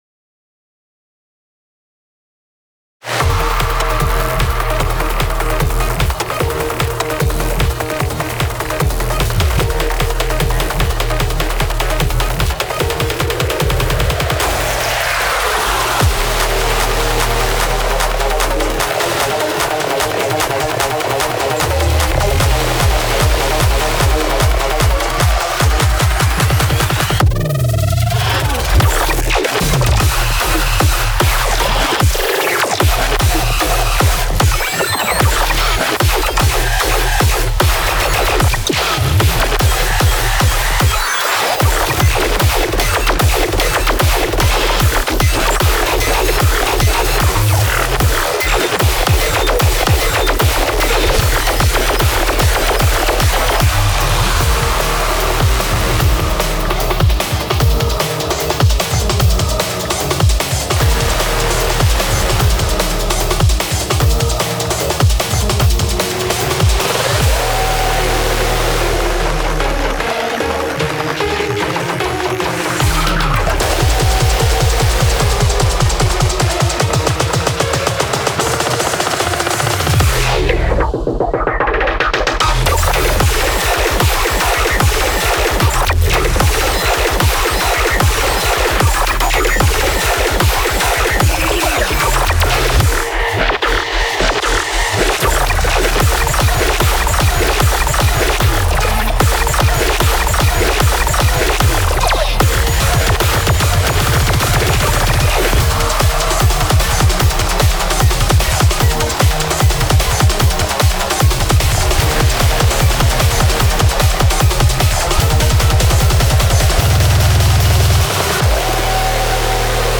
BPM150
Audio QualityLine Out